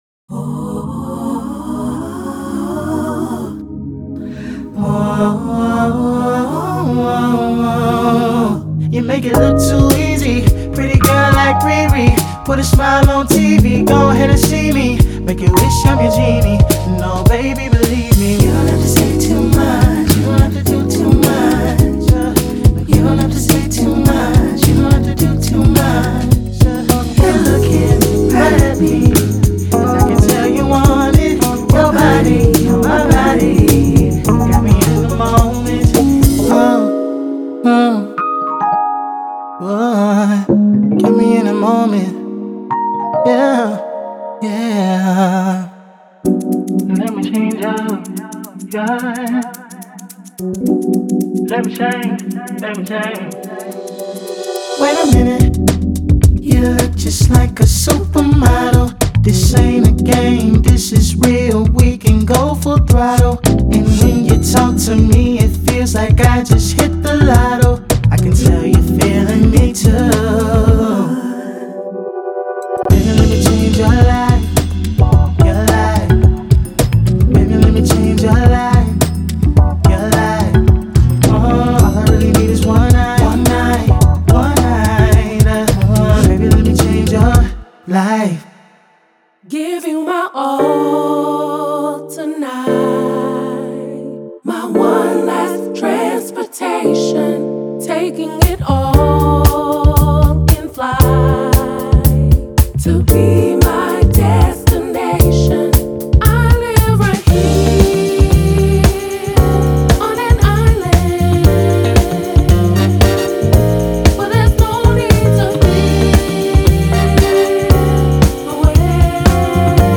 Genre:Soul and RnB
BPM106から155までのテンポで、アップビートなリズムとスローテンポなメロディが完璧にミックスされています。
デモサウンドはコチラ↓